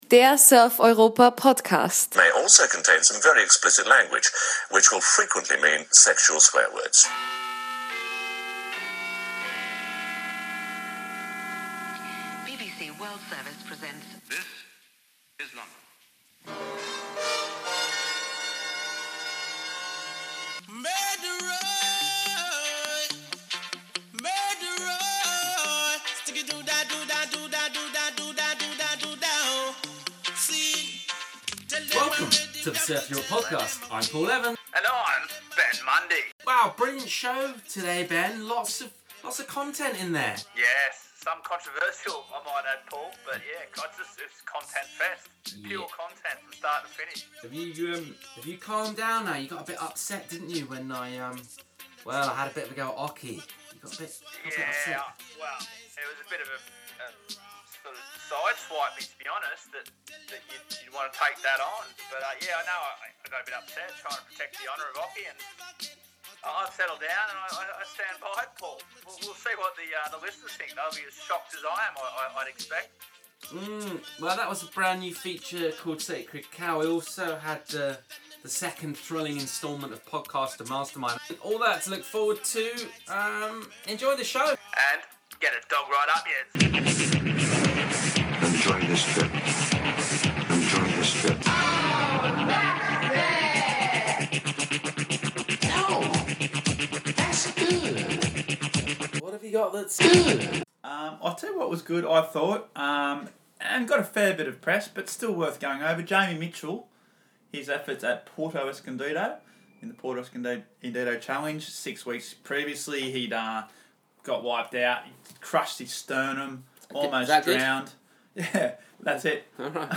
Warning: Contains strong language, including sexual swear words.